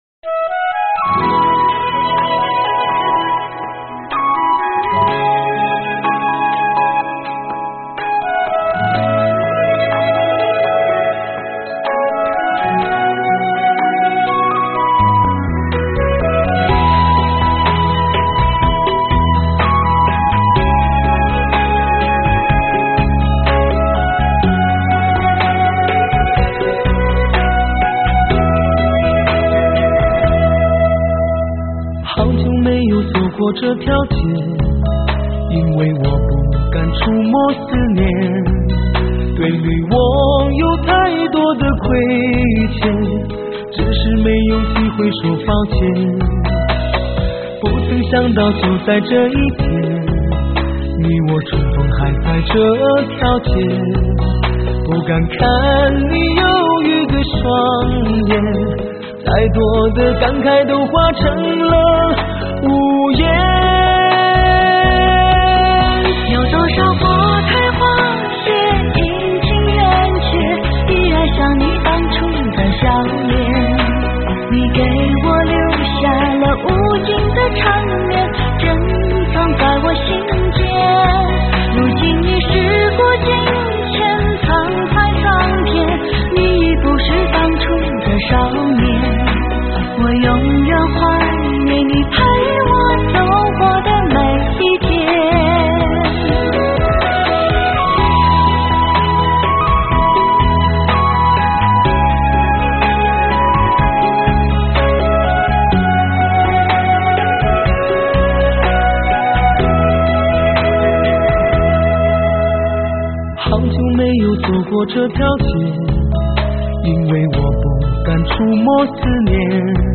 无与伦比的极致人声 让心灵无限释放
顶级HI-FI试音人声